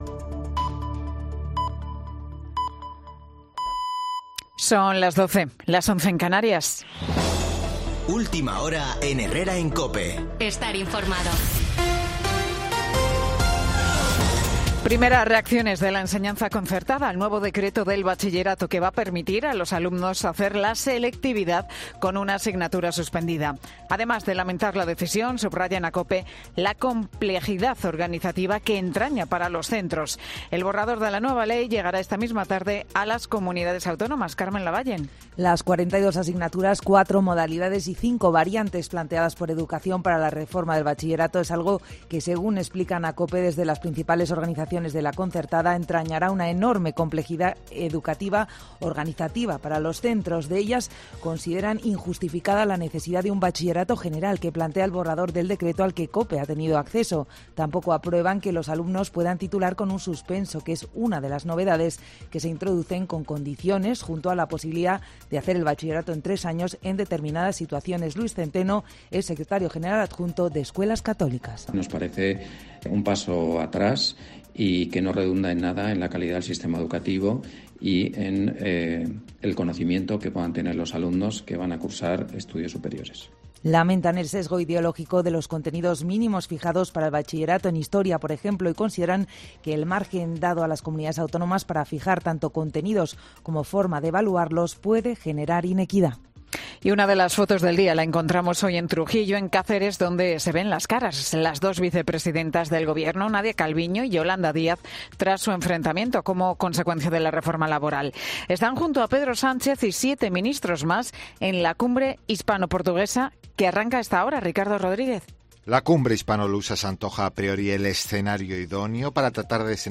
Boletín de noticias COPE del 28 de octubre 2021 a las 12:00 horas